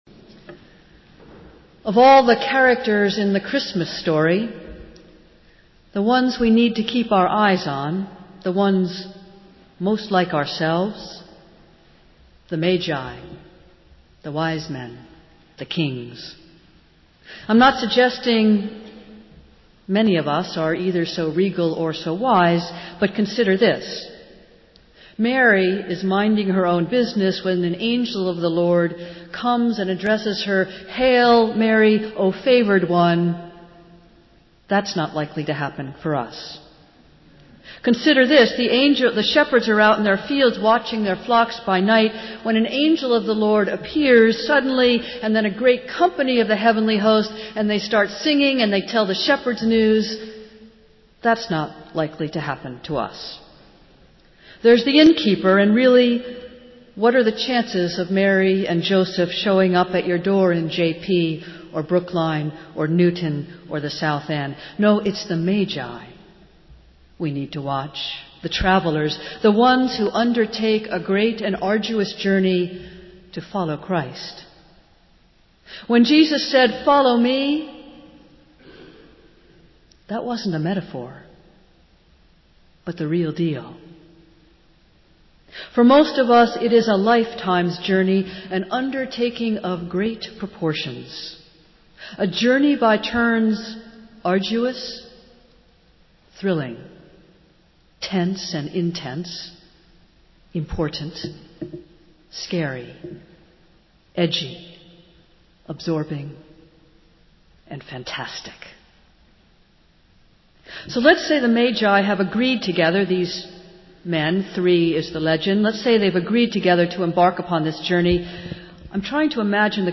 Festival Worship - Three Kings Sunday